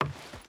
Wood Walk 3.ogg